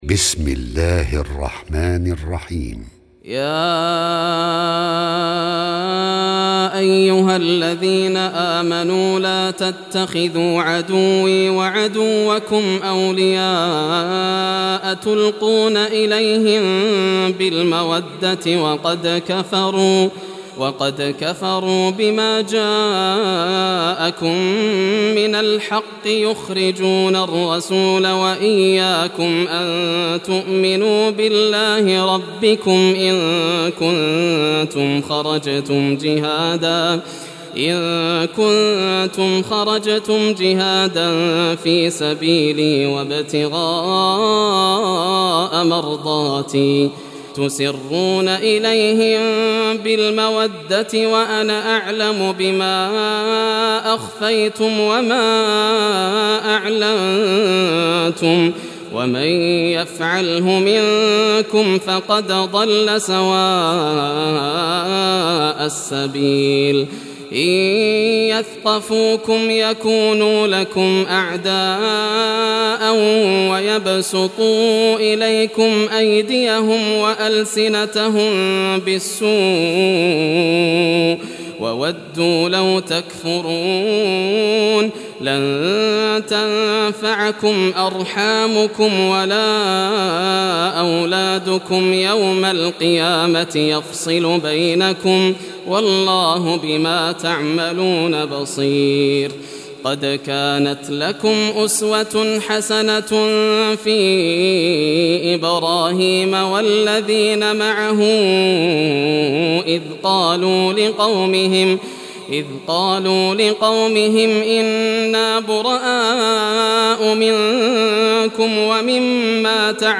القران الكريم بصوت القارى ياسر الدوسري كامل